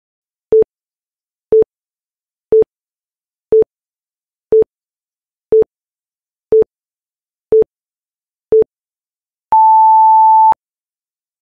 snackattack-beep.mp3